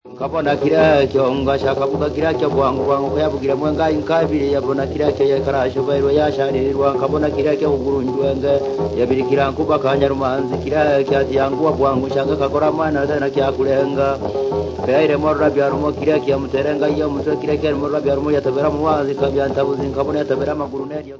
Folk music
Field recordings
sound recording-musical
The singer was word perfect throughout the whole recitation. He tells the locally well known legend of the Lake Victoria Nyanza.
The instrument had to be returned before the end of the legend, as one or two strings were slightly flat.
The Legend of spirit of the Lake, with Nanga trough Zither.